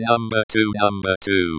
Audio test: Localizzazione spaziale del suono
06-number-two.wav